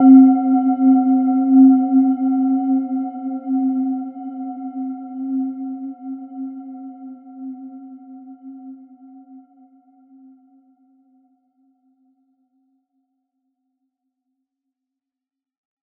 Gentle-Metallic-4-C4-f.wav